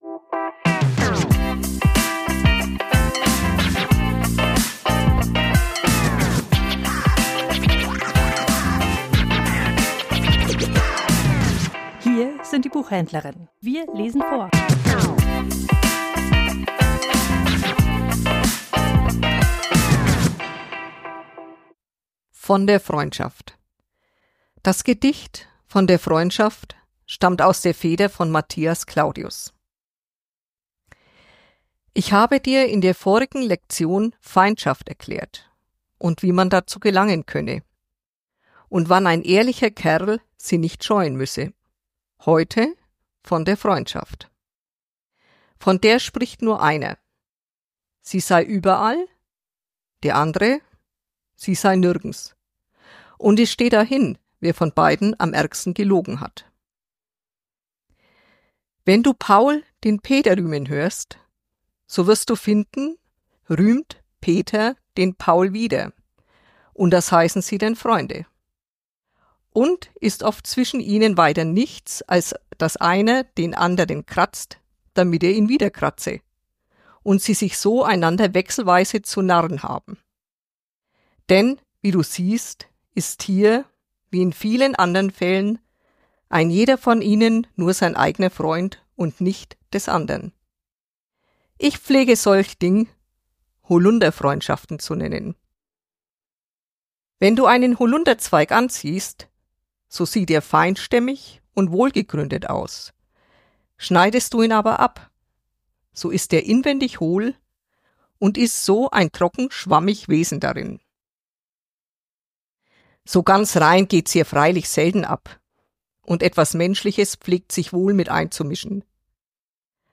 Vorgelesen: Von der Freundschaft
liest das Gedicht